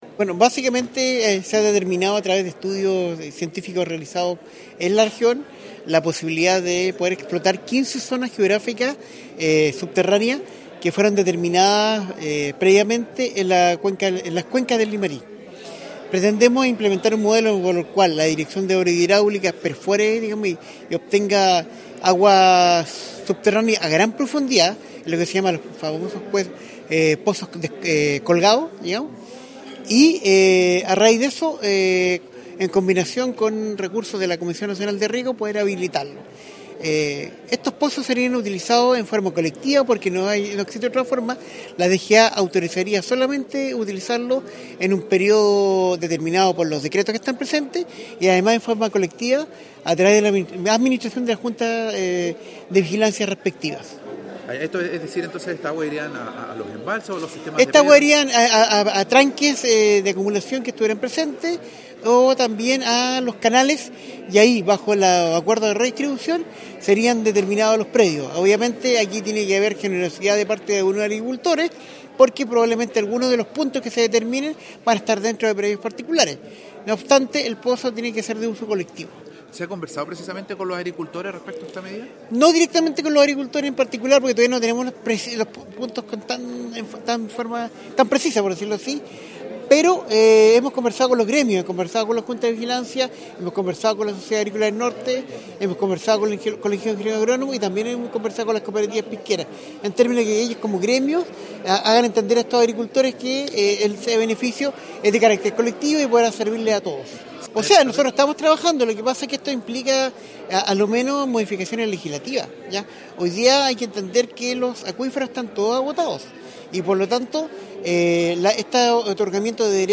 Fue el segundo encuentro regional de coordinación de estrategias para enfrentar la crisis hídrica reunió a parlamentarios, autoridades de Gobierno, consejeros regionales, dirigentes sociales, empresarios, agricultores y crianceros que plantearon sus problemáticas para ir levantando medidas para hacer frente a esta crisis.
Christian Álvarez, Seremi de Agricultura, indicó que
2-ENCUENTRO-AGUA-Seremi-Agricultura-Cristian-Alvarez.mp3